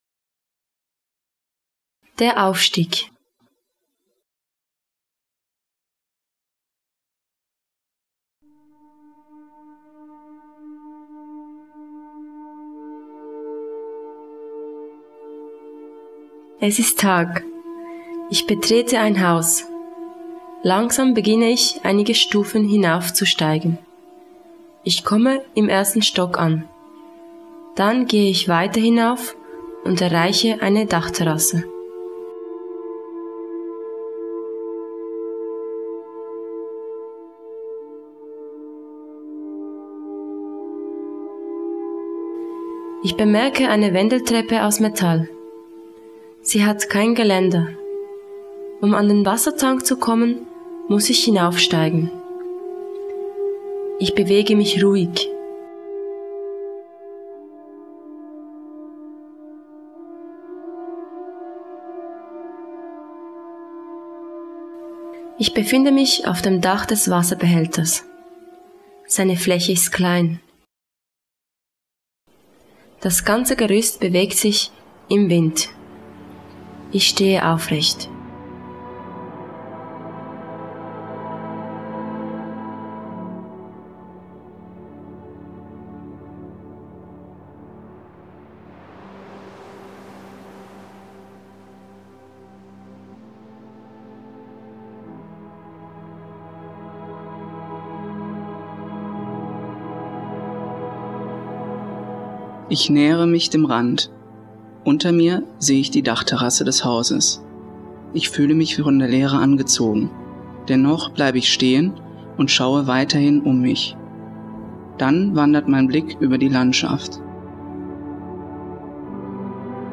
Geleitete Erfahrung